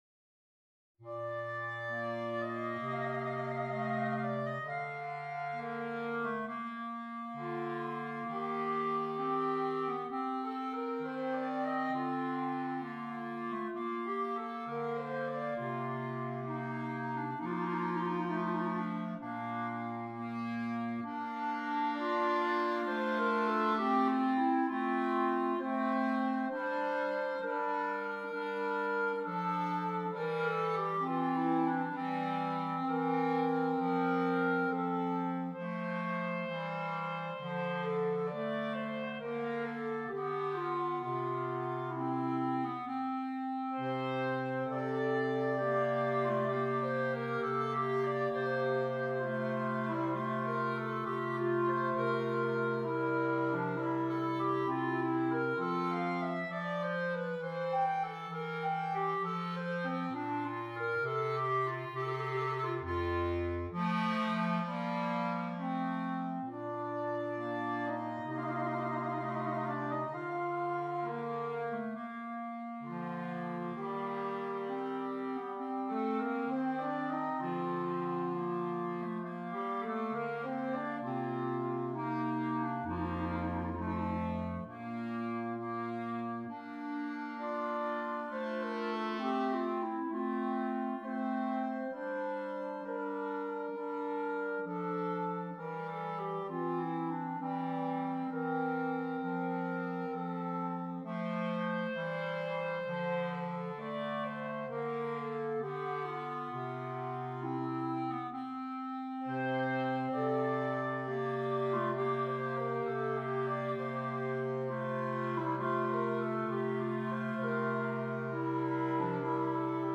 3 Clarinets, Bass Clarinet